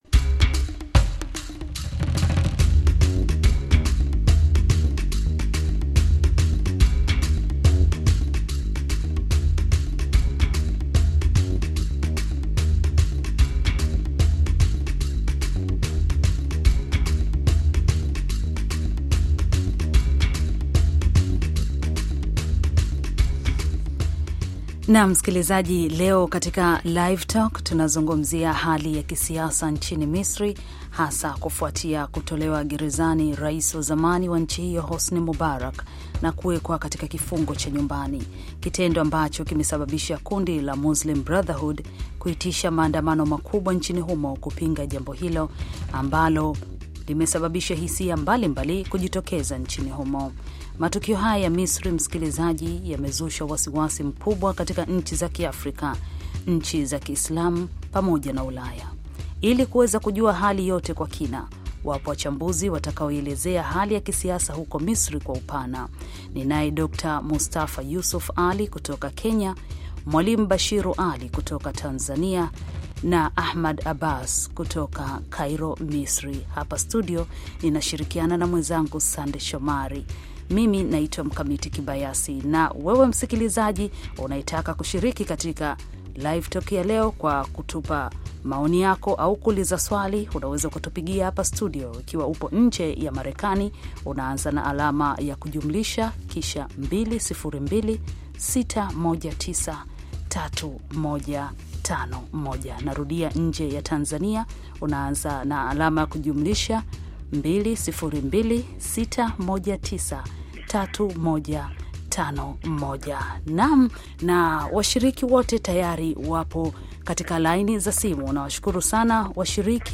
Live Talk - hali ya kisaisa Misri